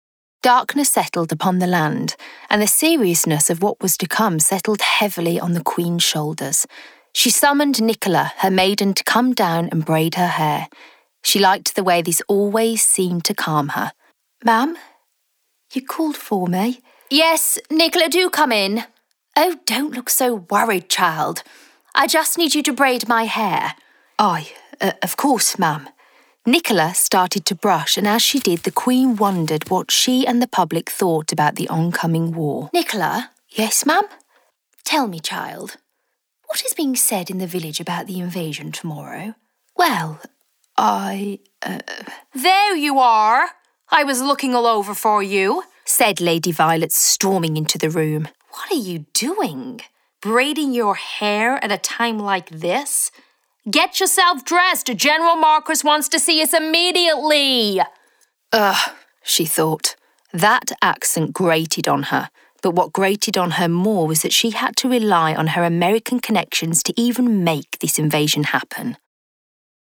Voice Reel
Audiobook
Audiobook.mp3